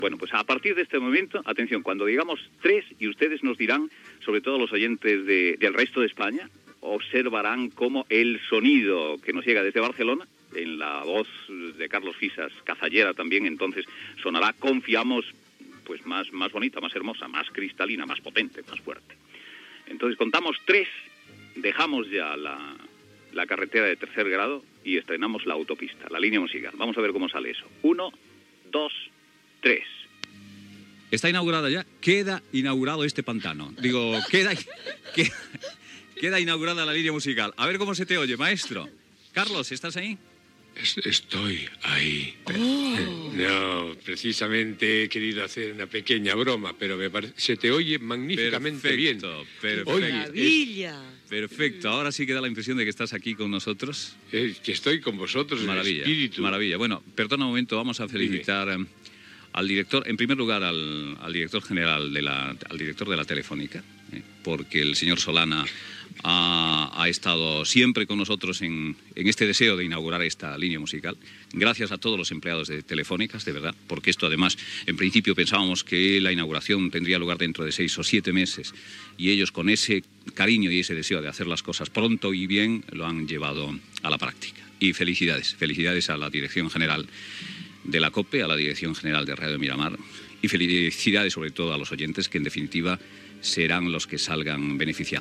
Quan la Cadena COPE va passar de les línies microfòniques, de qualitat baixa, a les línies musicals que donaven un so de molta qualitat.
Info-entreteniment